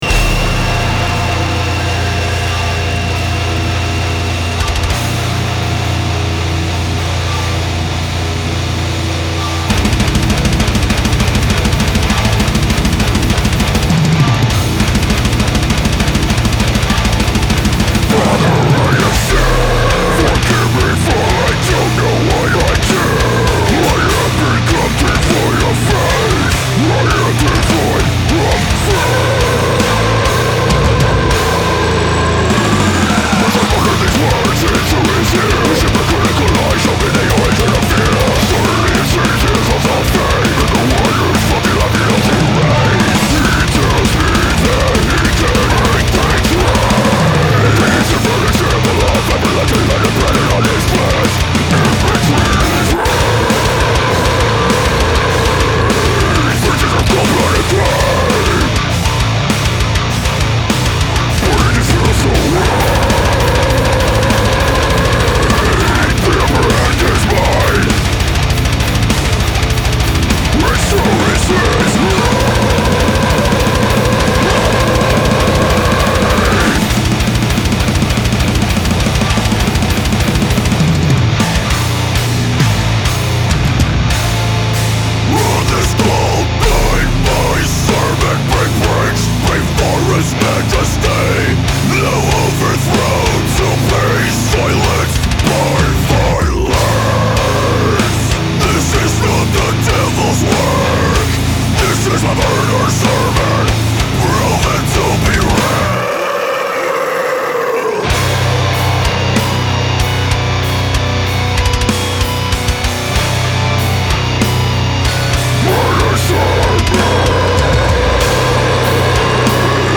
WhitechapelMurderSermon_allmp3__1.mp3